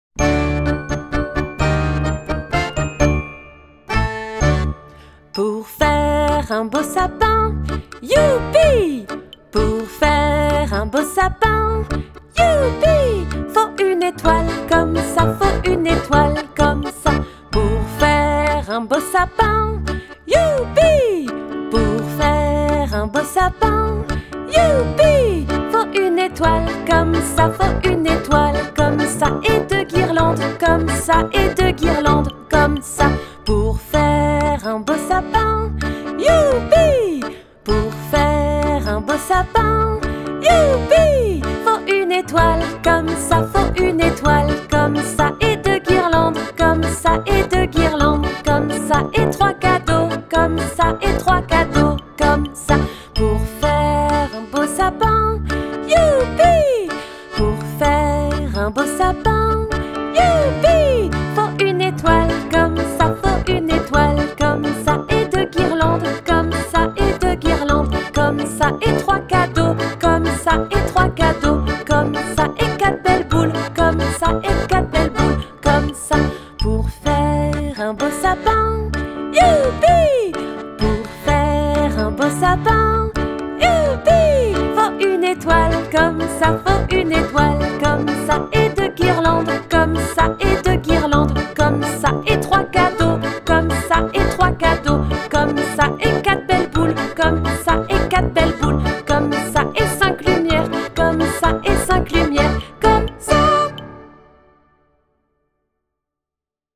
Comptines mathématiques
piano, accordéon